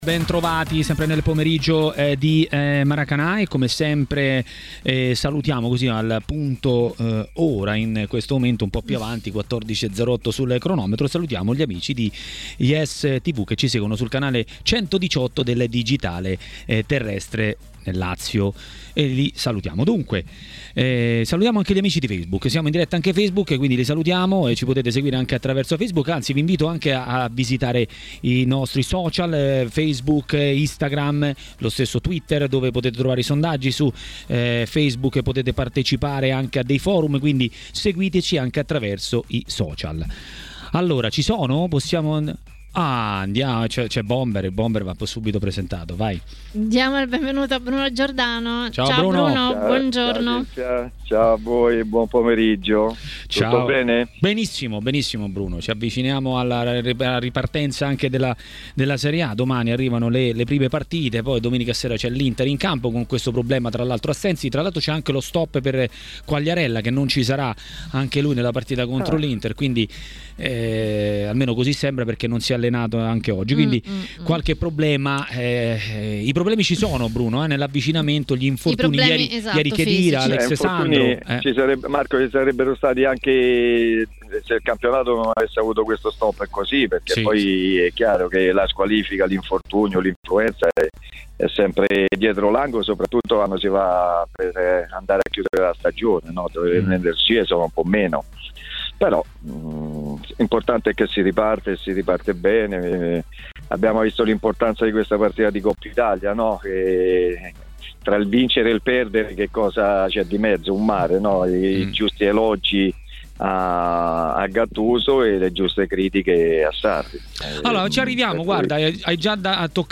L'ex attaccante Bruno Giordano a TMW Radio, durante Maracanà, è intervenuto per parlare degli argomenti del momento.